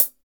FUNKY CHH F.wav